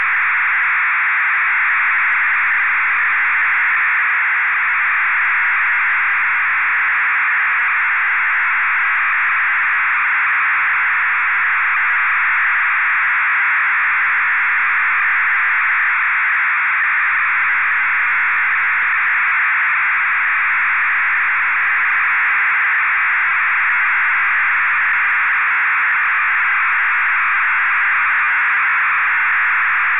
Запись сигнала на опознание, 3850 кГц, USB
Частота 3850 кГц, USB.
Вещественный сигнал, 32 кГц, 16 бит, моно.